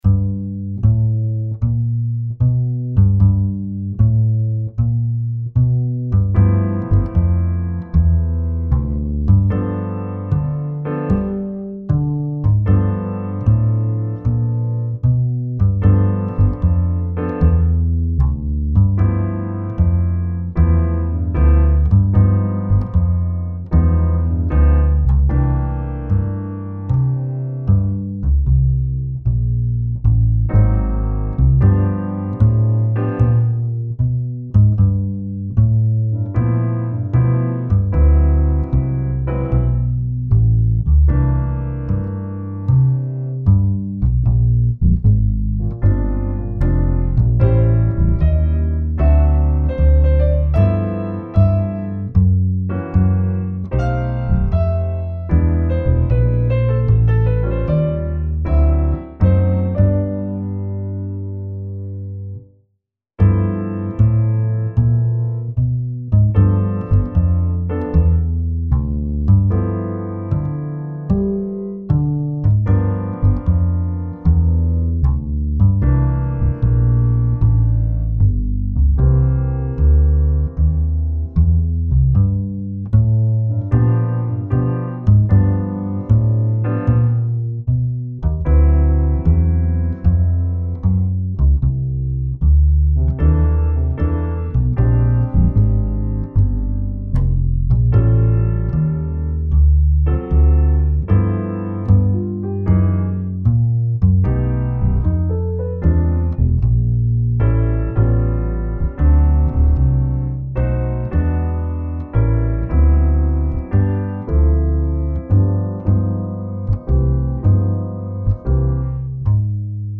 Adagio [70-80] plaisir - piano - amis - nostalgie - aperitif